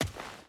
Footsteps
Dirt Run 2.wav